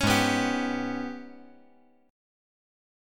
G#mM11 Chord
Listen to G#mM11 strummed